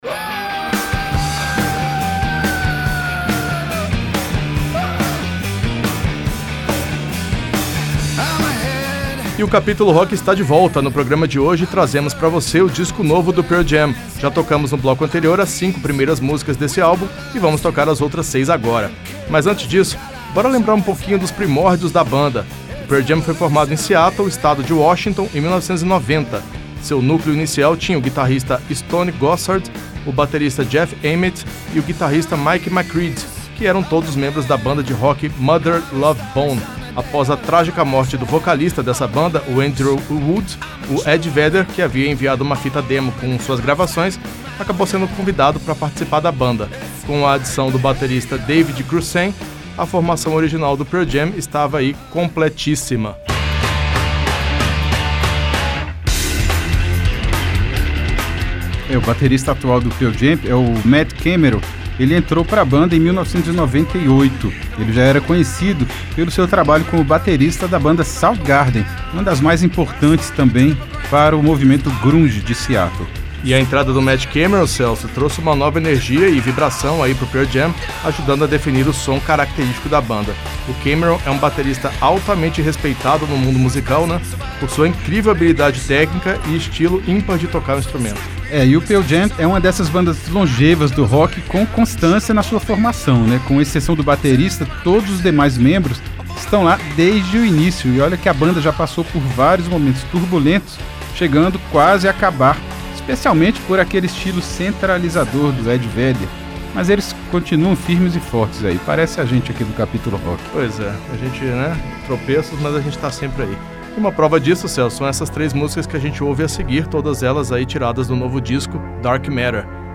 São 11 faixas em 48 minutos de música.
O resultado é um álbum clássico de rock de garagem
guitarrista